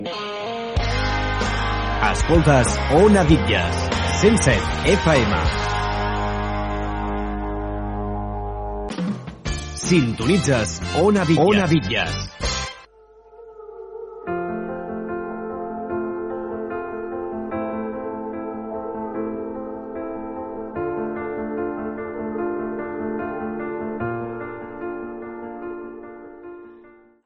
Indicatius de l'emissora i tema musical